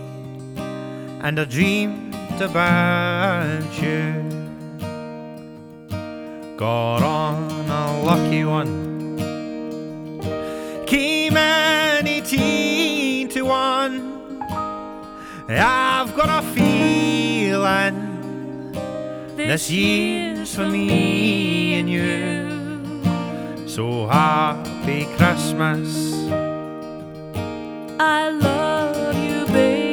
• Singer/Songwriter